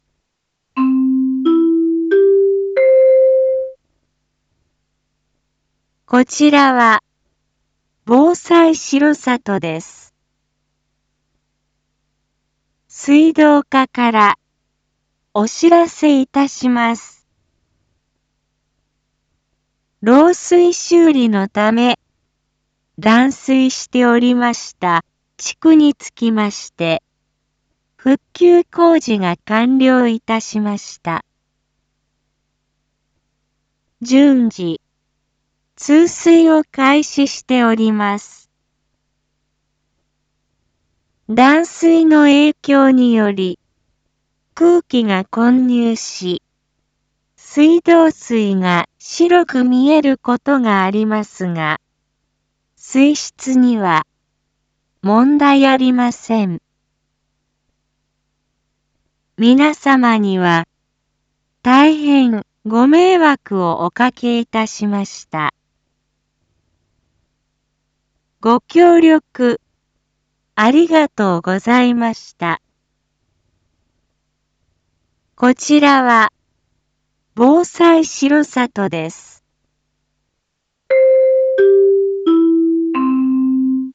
Back Home 一般放送情報 音声放送 再生 一般放送情報 登録日時：2022-01-13 15:51:25 タイトル：R4.1.13 漏水工事完了（七会地区のみ） インフォメーション：こちらは、防災しろさとです。